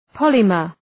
Προφορά
{‘pɒləmər}